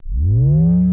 menu_fade.wav